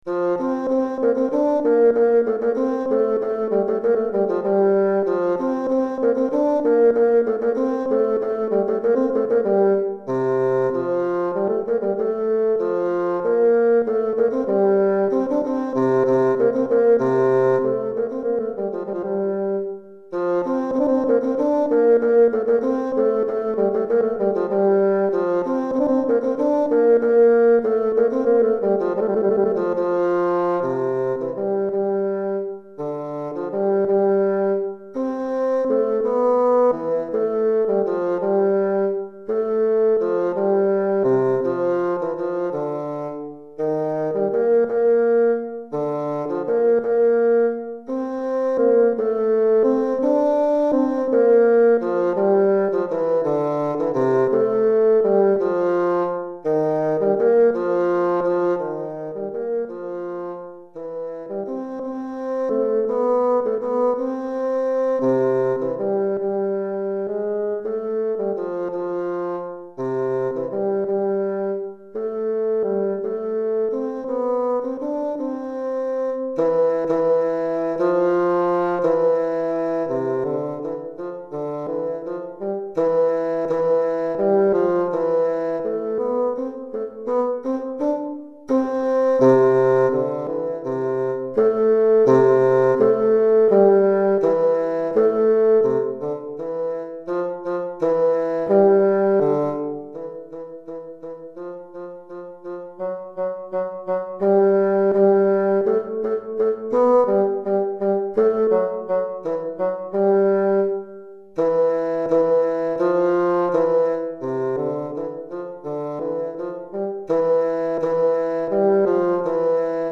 Basson Solo